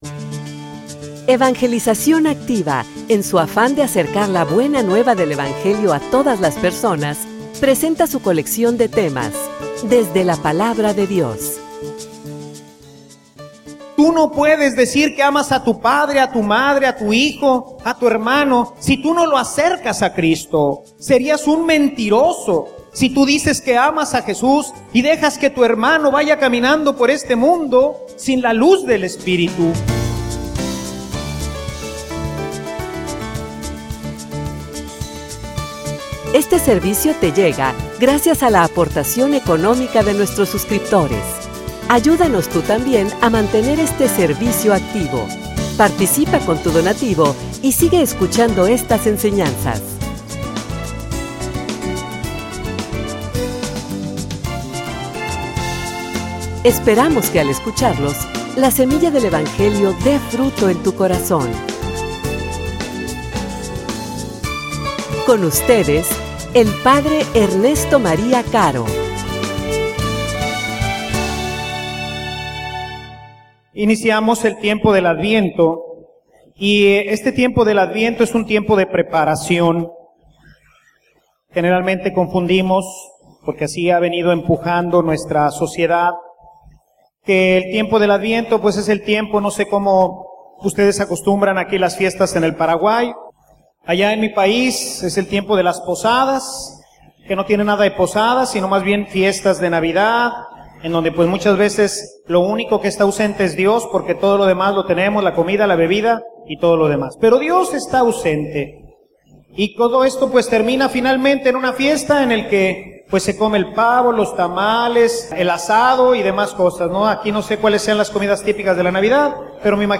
homilia_Un_adviento_que_acerque_a_los_demas.mp3